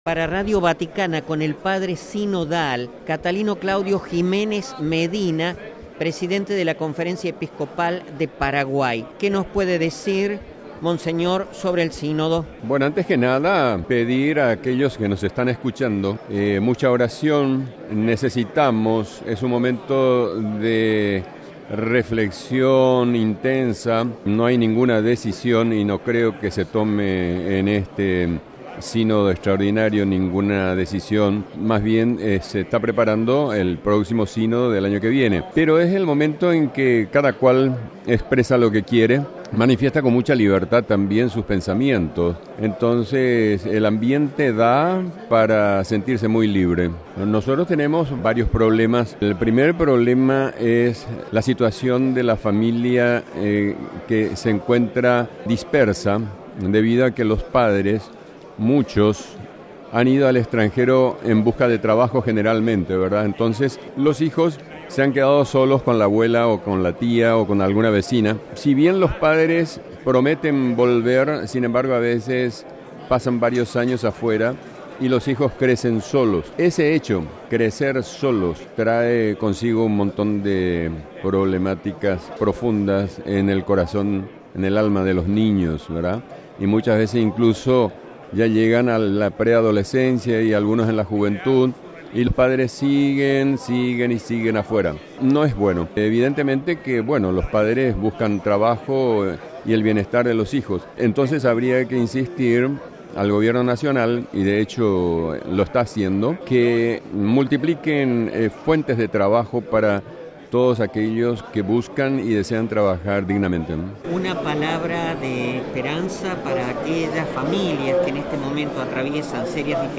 Sobre las consecuencias que generan las migraciones a las familias, habla el Presidente de los obispos paraguayos
MP3 Mons. Catalino Claudio Giménez Medina, Presidente de la Conferencia Episcopal de Paraguay y Padre Sinodal, destacó en su entrevista para Radio Vaticano las consecuencias que tienen para las familias la migración de los padres por motivos de trabajo. Los más afectados, como él destacó, son los hijos que viven sin la presencia de sus progenitores en momentos claves de la vida como son la infancia o la adolescencia.